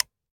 weap_mike_disconnector_plr_01.ogg